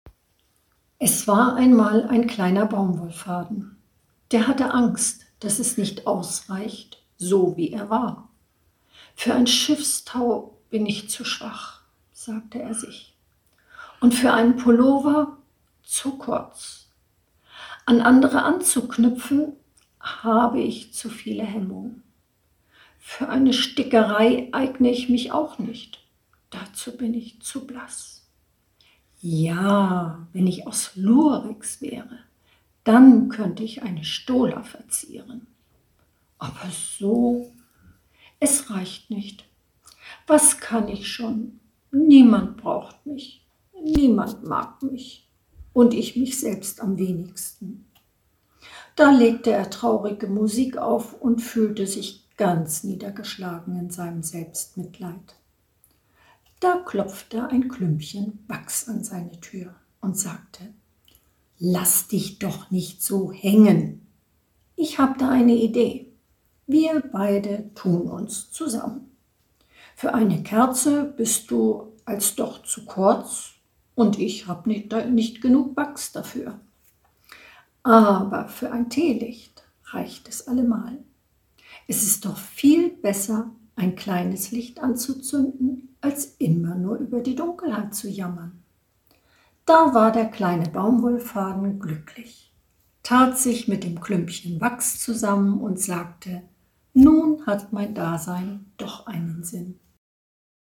06 Kleiner Baumwollfaden - eine kleine Weihnachtsgeschichte